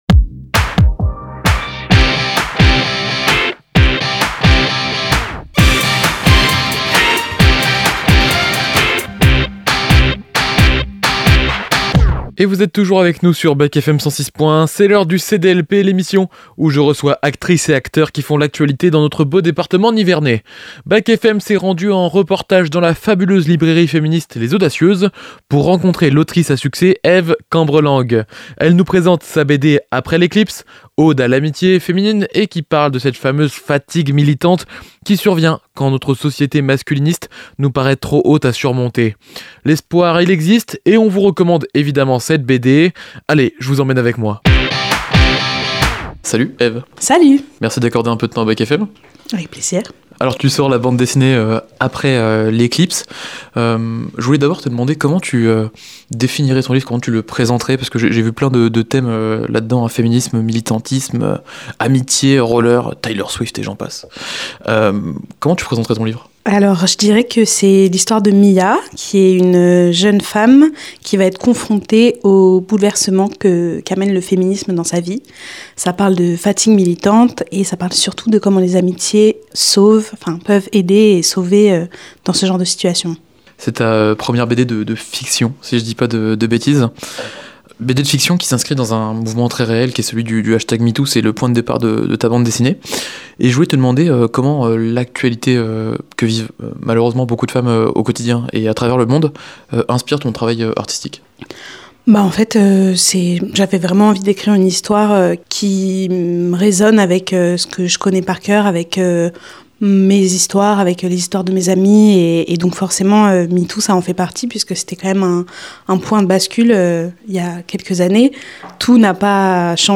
C'est dans les parages - Interview